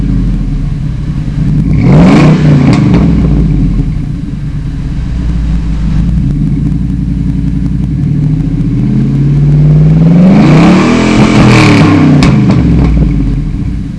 Engine:  4.6L Ford mustang GT V-8.
Flowmaster 40 series dual exhaust, Pillar pod mounted tachometer,